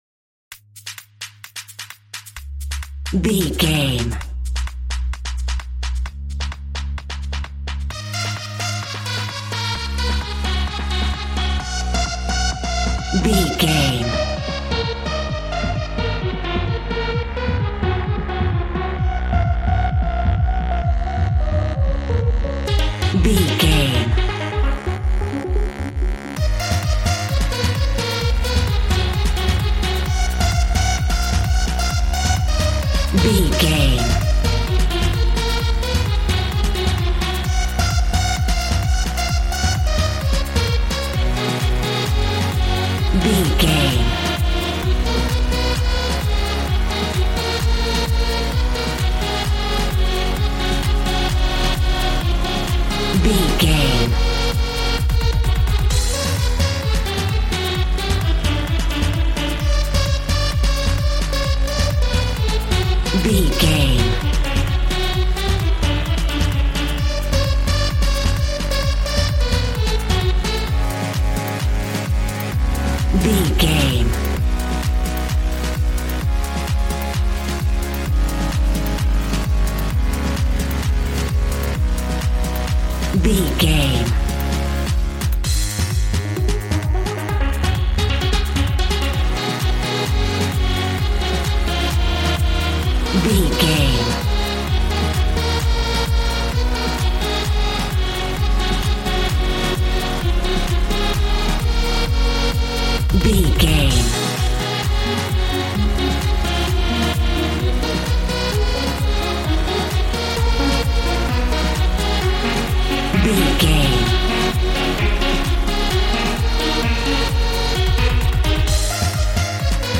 Ionian/Major
G♭
Fast
groovy
energetic
synthesiser
drums